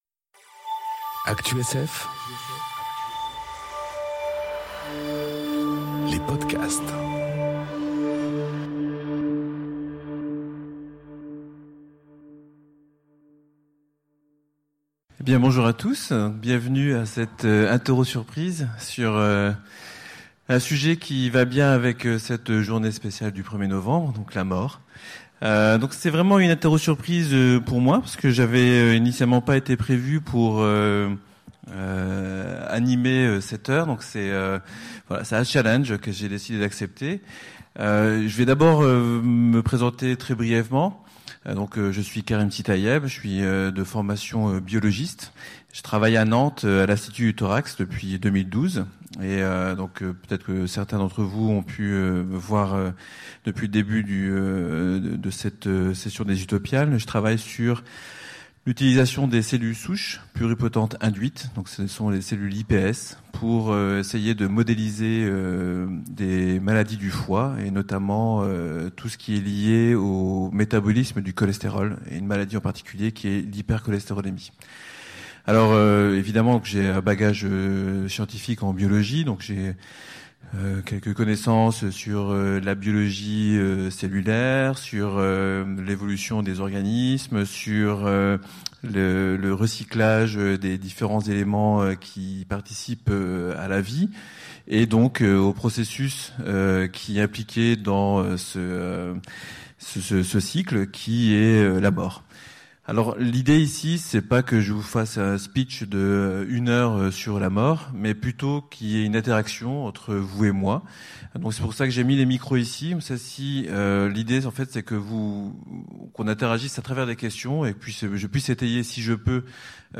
Interro surprise La mort enregistrée aux Utopiales 2018